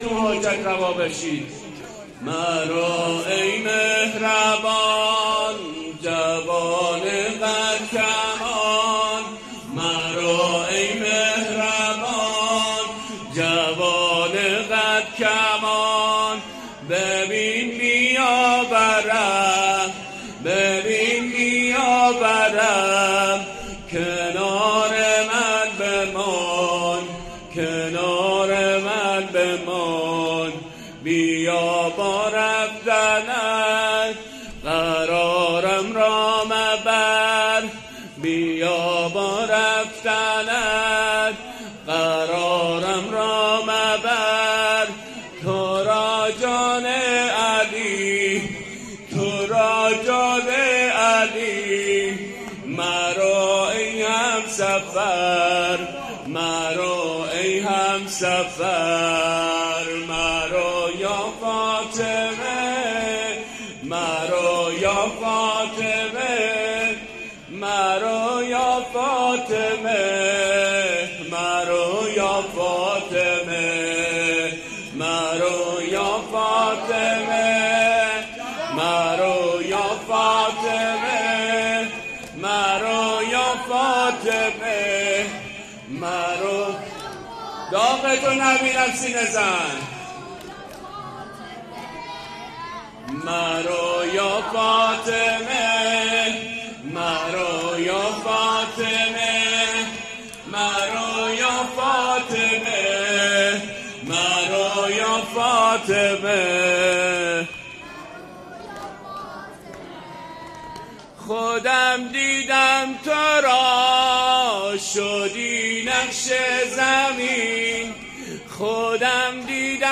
ذاکر و شاعر اهل بیت علیهم السلام